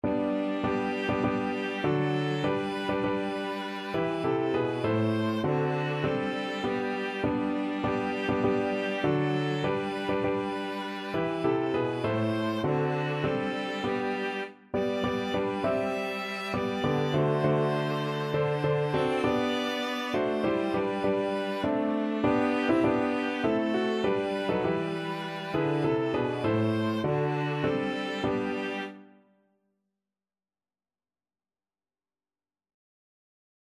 Free Sheet music for Piano Trio
ViolaCelloPiano
G major (Sounding Pitch) (View more G major Music for Piano Trio )
3/4 (View more 3/4 Music)
Traditional (View more Traditional Piano Trio Music)